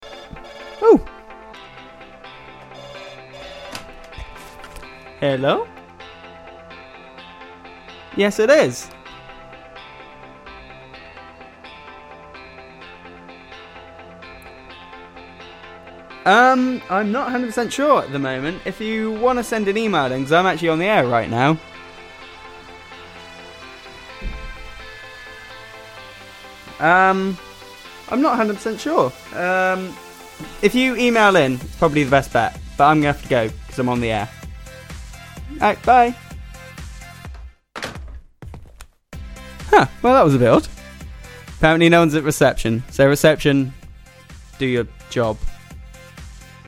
This is how to (not) answer a phone call while you're live on the airwaves.